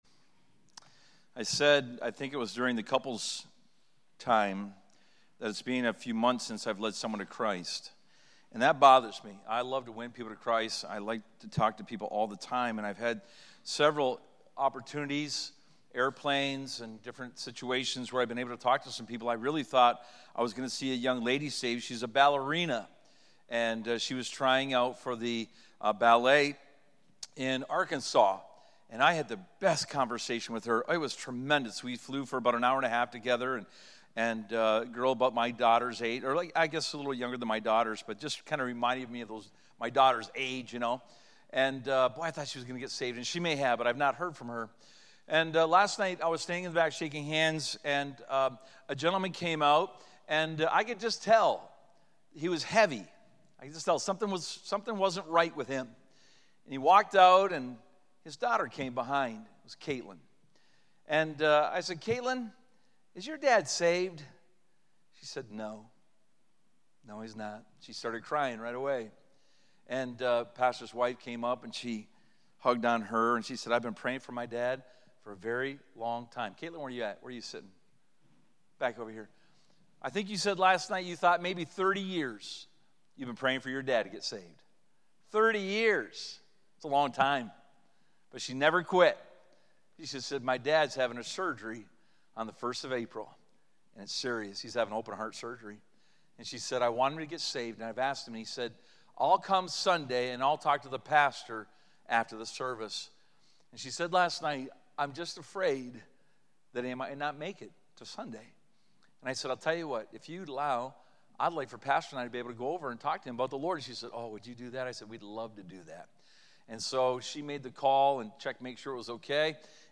Sermons | First Baptist Church
Tuesday Night Spring Revival 2025